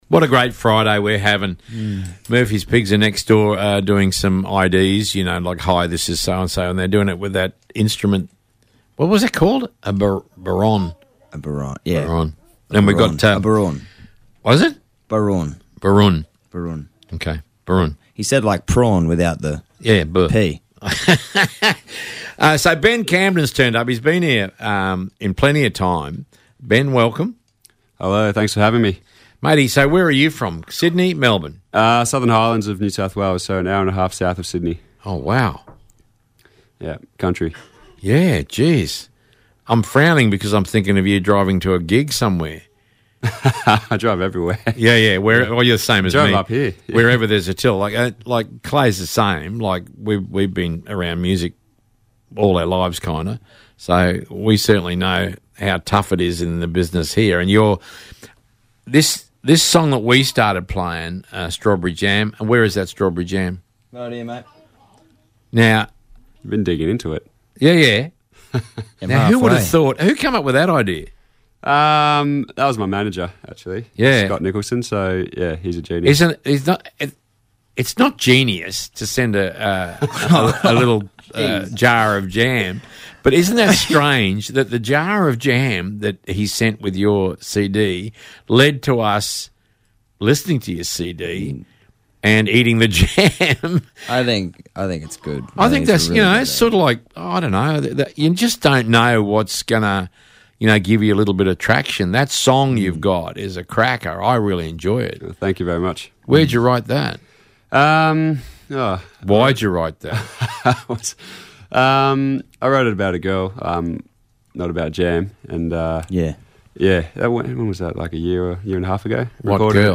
Great interview with an even better live song.